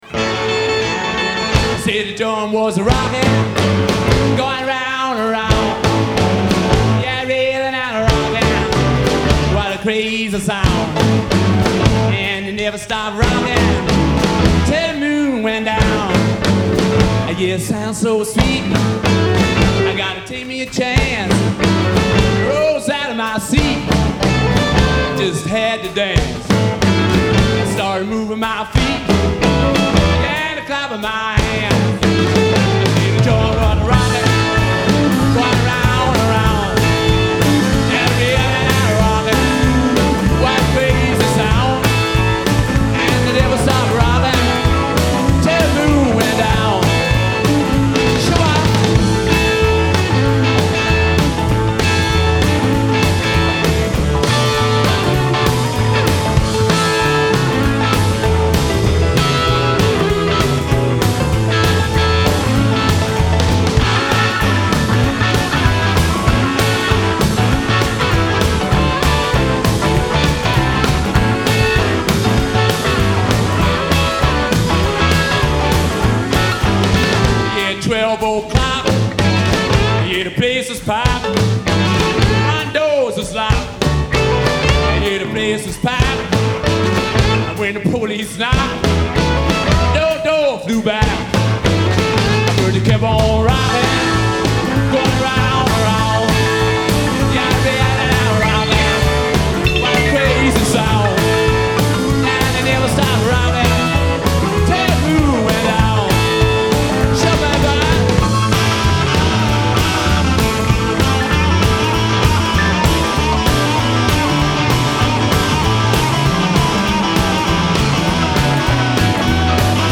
Genre : Pop, Rock